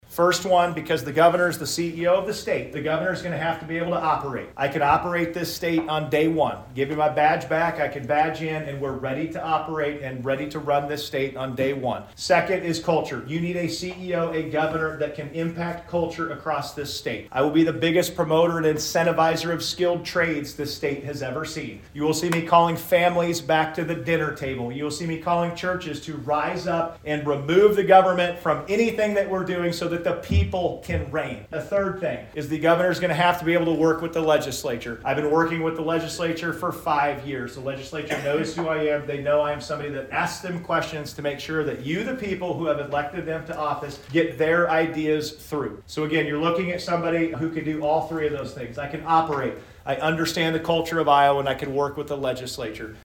Carroll County Republican Committee Hosts Candidates Running For Governor In GOP Forum Wednesday Evening
Not an empty seat could be found at the Santa Maria Winery, with attendees having a chance to socialize, hear directly from the GOP’s hopefuls, and having small group discussions with the contenders.
gop-forum-2.mp3